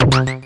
游戏SFX " 浪潮01
描述：就像一个有节拍的波浪
Tag: 实验室 游戏 计算机 空间战 机器人 损伤 街机 激光 视频游戏